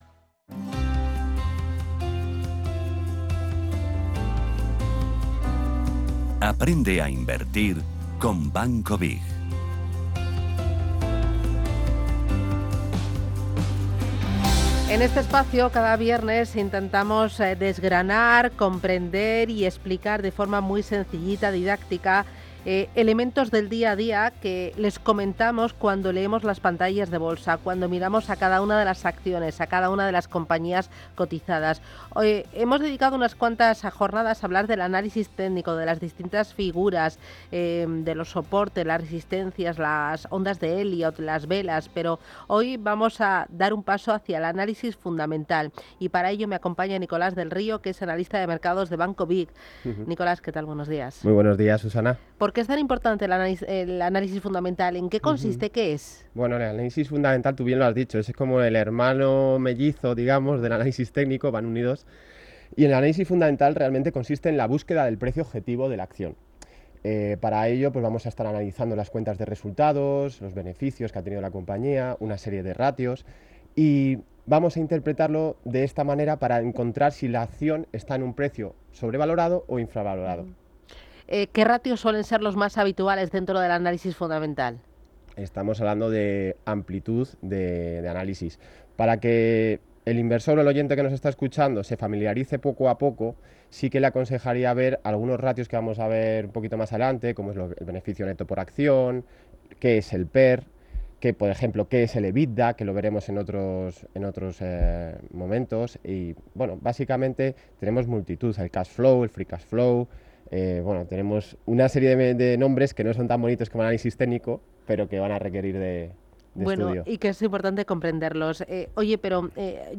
Sección "Aprende a Invertir con BiG" junto a Radio Intereconomía